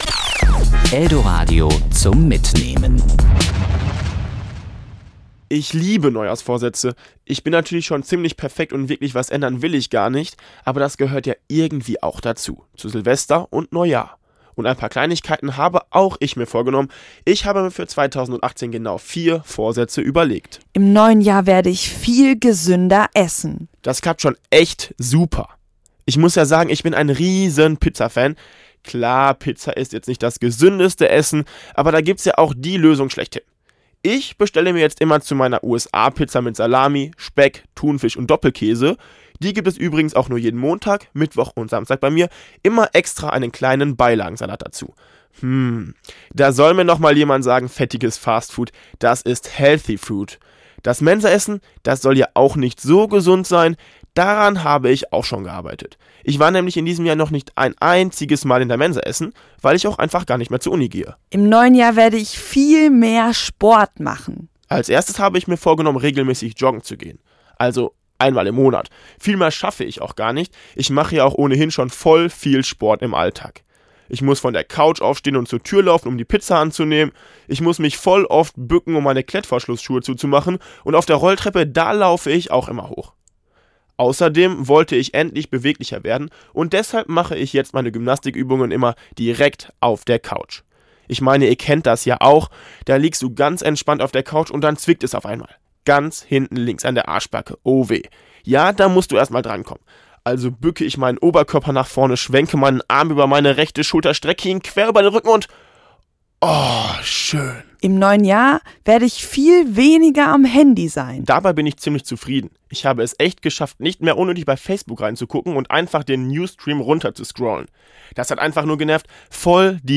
Serie: Glosse  Sendung: KURT